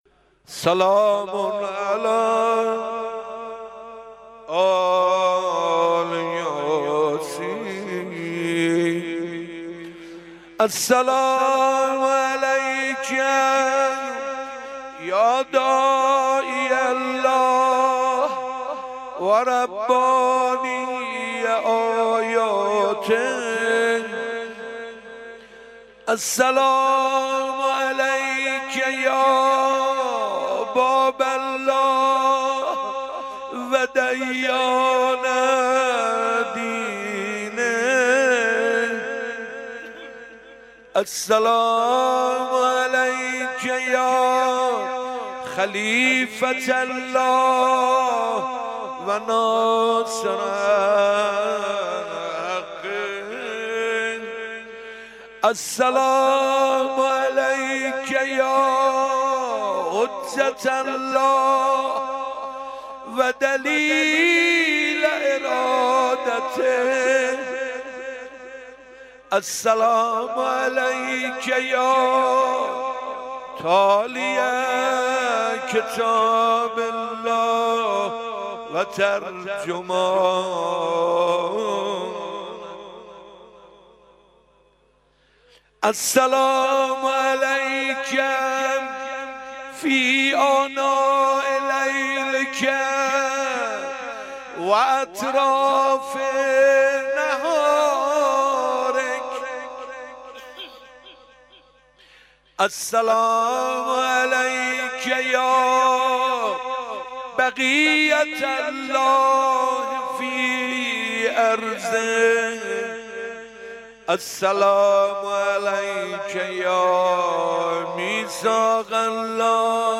5 مرداد 97 - بیت الشهدا - قرائت زیارت آل یاسین